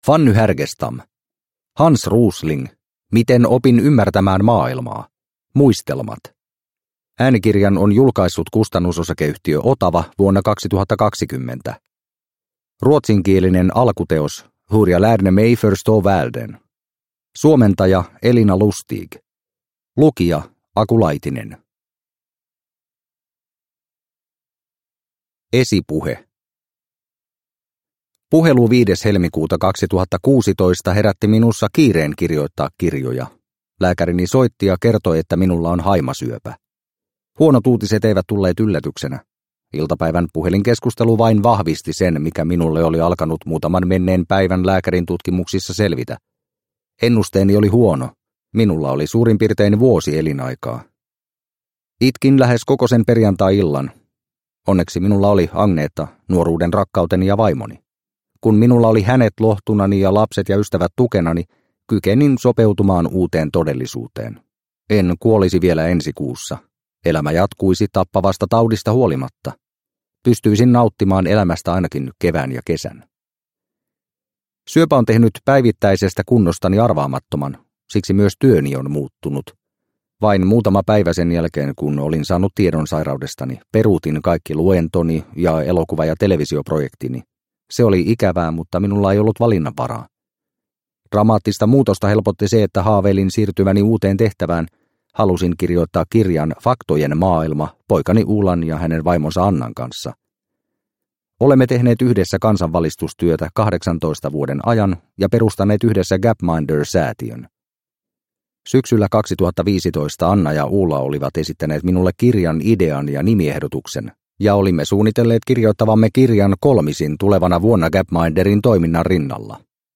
Hans Rosling – Ljudbok – Laddas ner